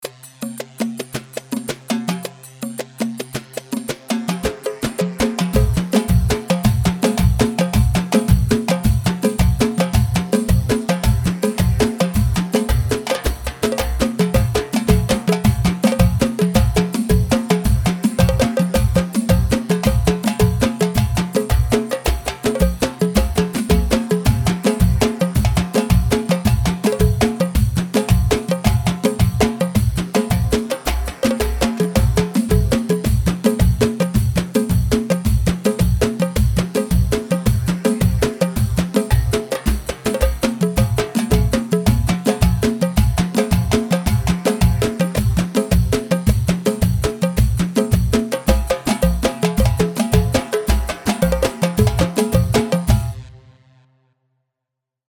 Dosari 4/4 109 دوسري
Dosari-4-4-109-Mix.mp3